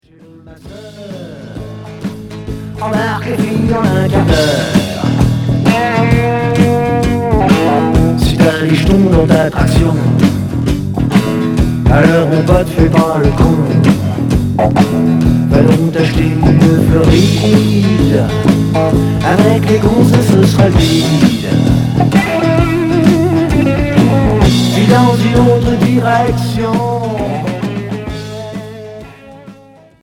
Rock 70's Unique 45t retour à l'accueil